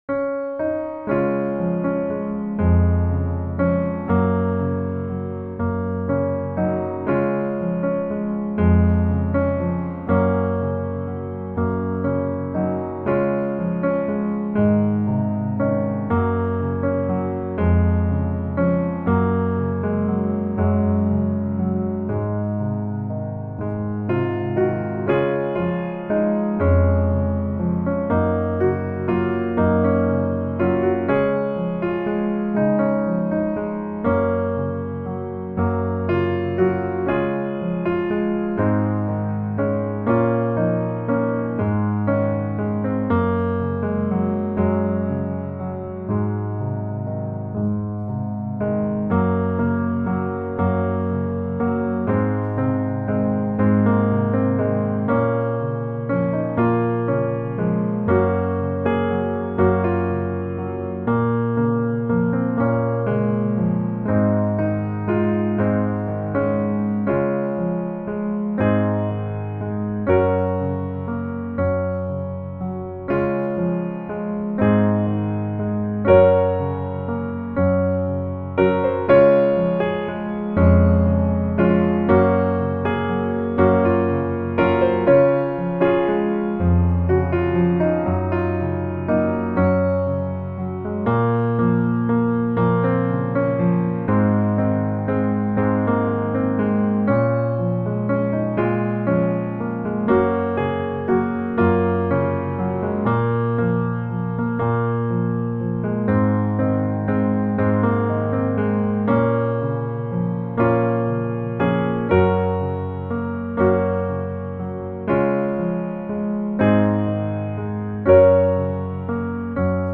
Db Majeur